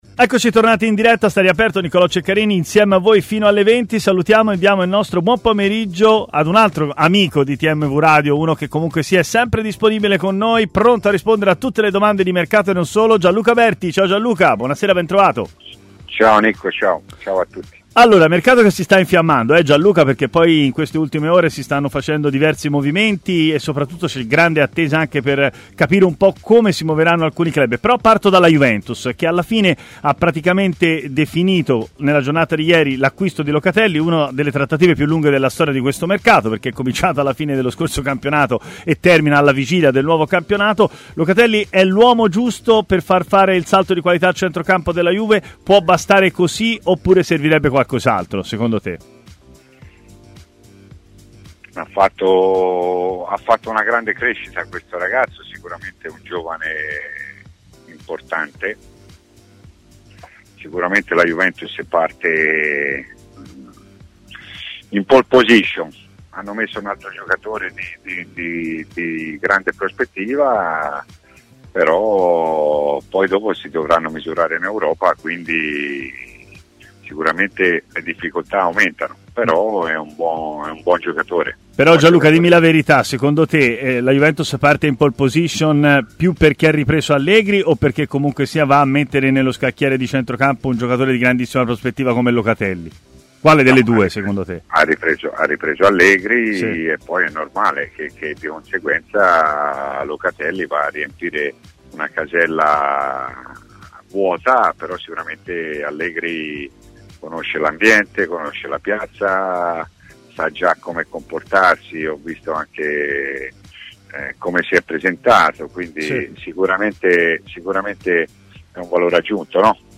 ha così parlato in diretta